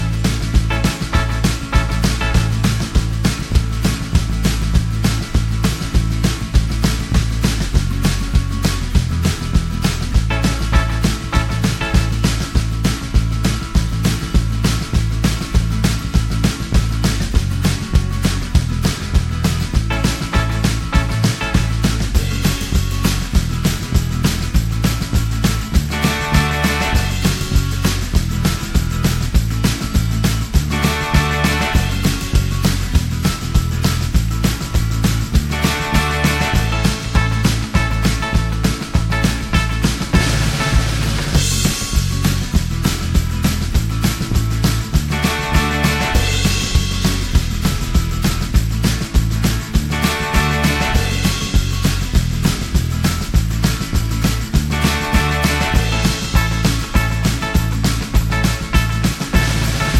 Minus Main Guitar For Guitarists 2:34 Buy £1.50